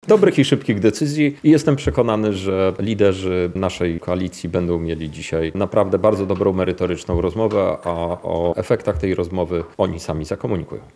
Europoseł Krzysztof Hetman, pytany o oczekiwane zmiany podczas konferencji prasowej w Lublinie, stwierdził, że spodziewa się dobrych i szybkich decyzji: – Dobrych i szybkich decyzji.